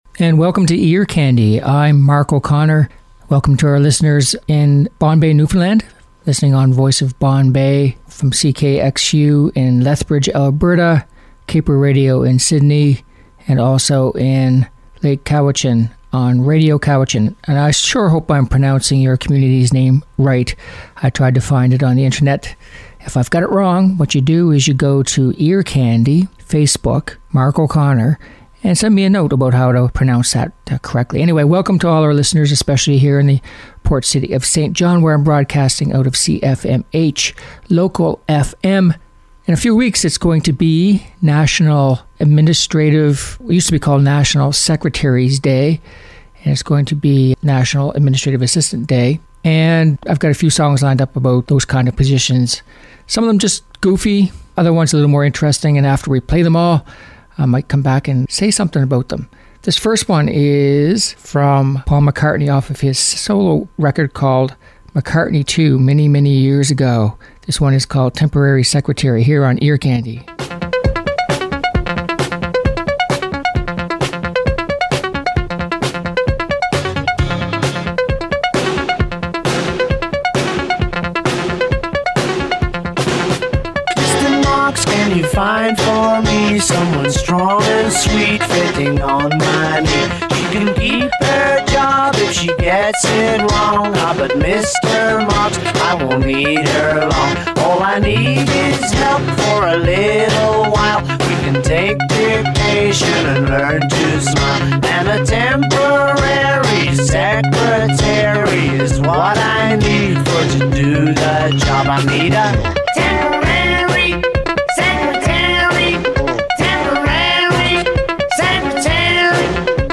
Upbeat Pop Songs